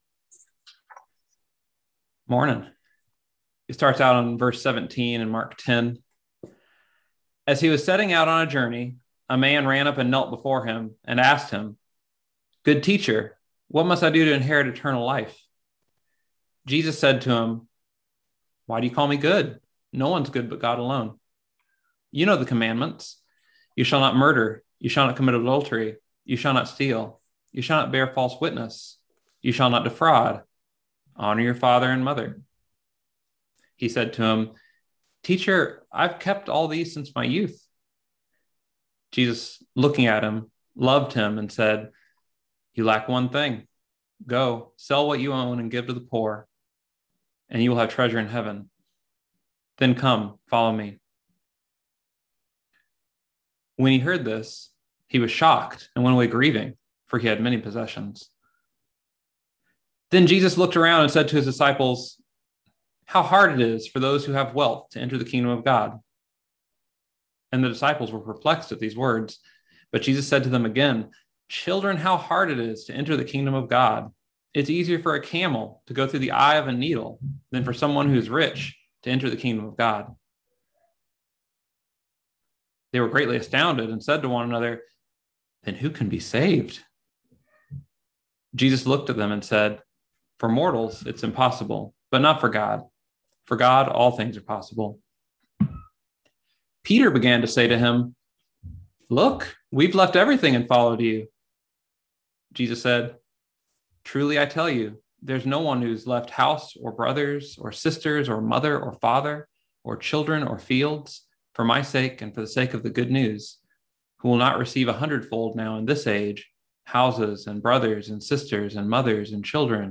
Listen to the most recent message from Sunday worship at Berkeley Friends Church, “Who Then Can Be Saved?”